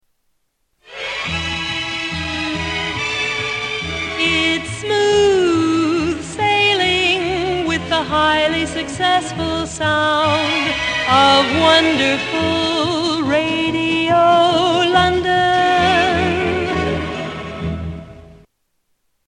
Tags: Radio Radio Stations Station I.D. Seques Show I.D